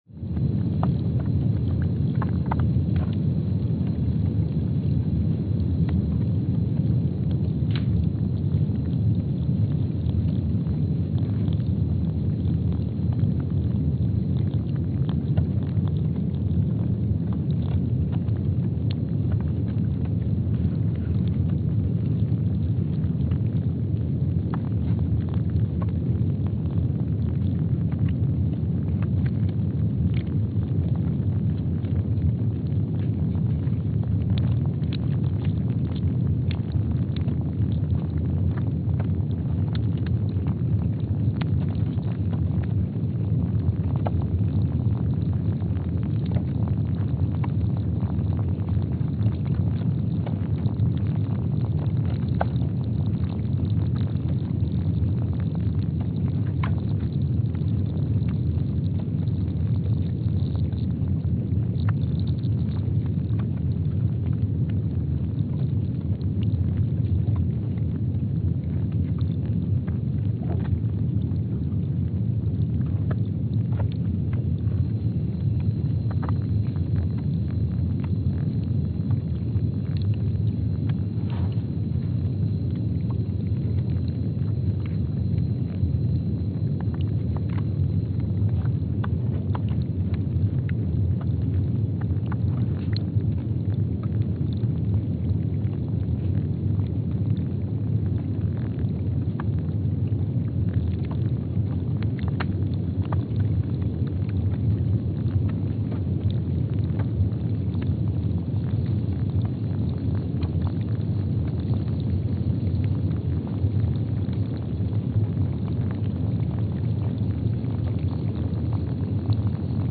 Palmer Station, Antarctica (seismic) archived on February 17, 2025
Speedup : ×500 (transposed up about 9 octaves)
Loop duration (audio) : 05:45 (stereo)
Gain correction : 25dB
SoX post-processing : highpass -2 90 highpass -2 90